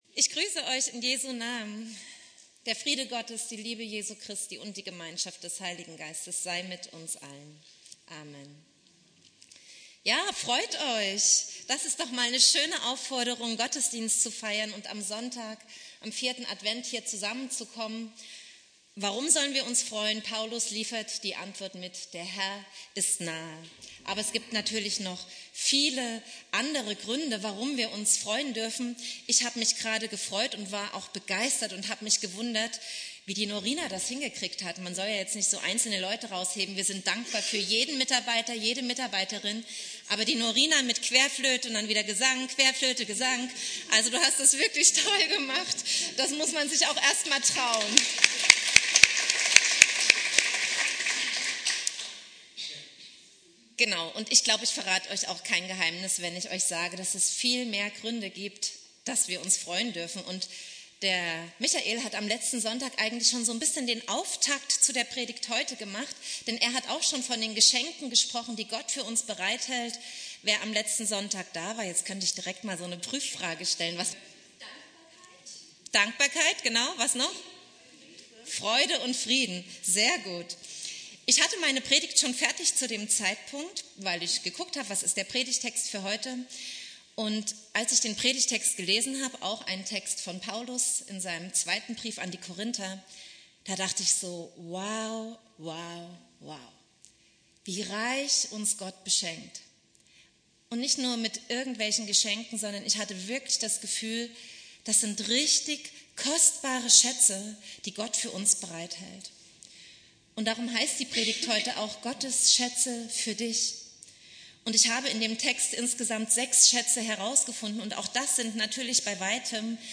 Predigt
4.Advent